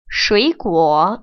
[shuĭguŏ] 수이구어